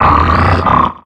Cri de Grahyèna dans Pokémon X et Y.